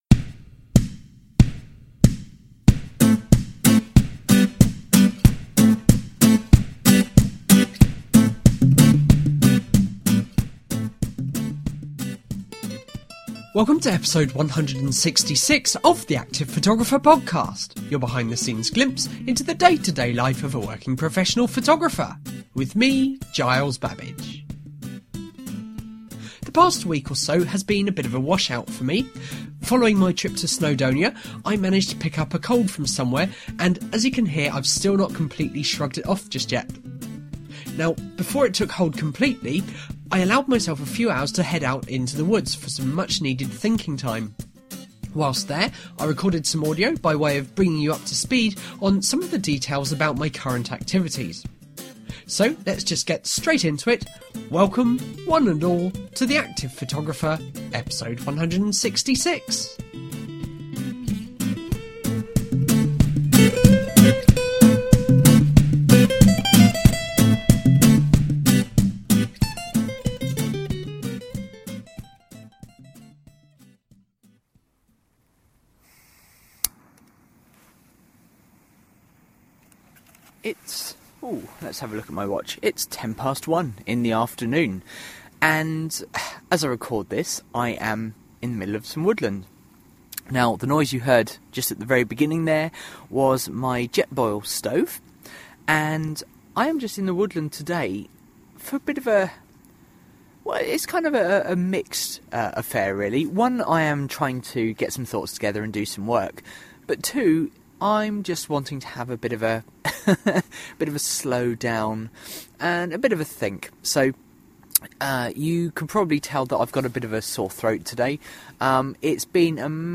This week: I’m sat in the woods, getting some thoughts together.